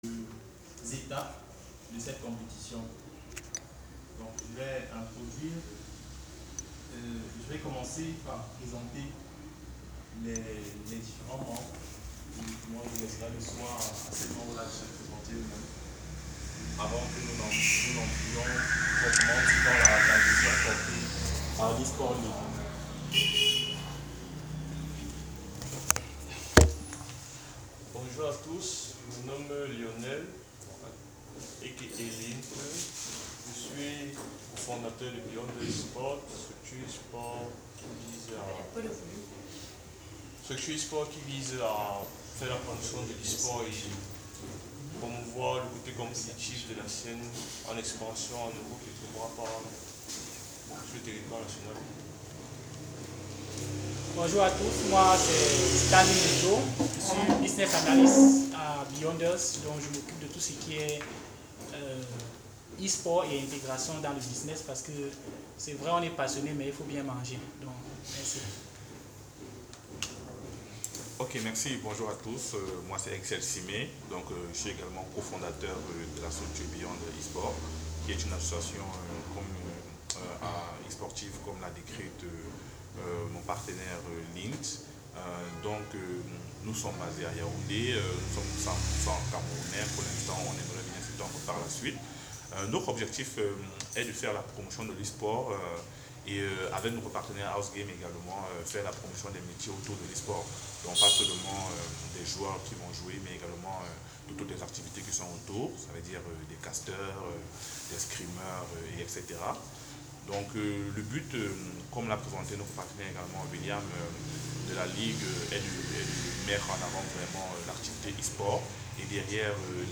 Samedi 1er novembre 2025, House Game et Beyonders ont tenu une conférence de presse à Jouvence pour annoncer le lancement officiel de l’E-Sport League 2026.